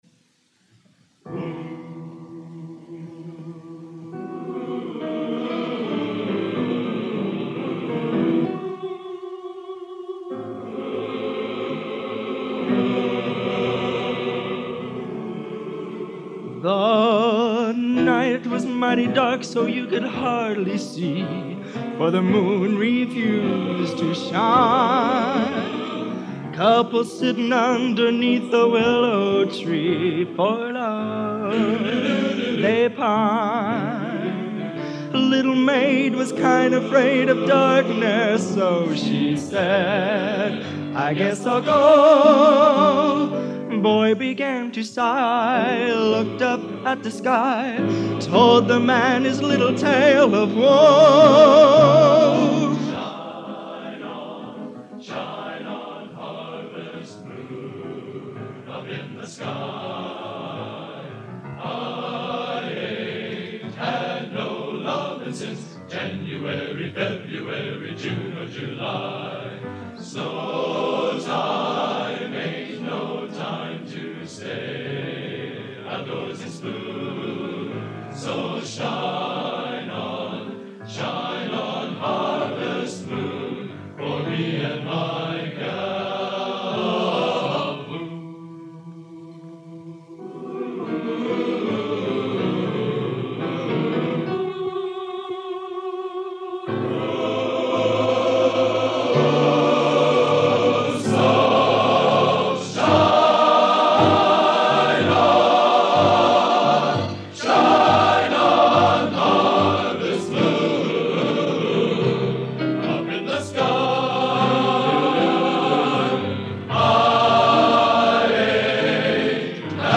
Genre: Popular / Standards Schmalz | Type: End of Season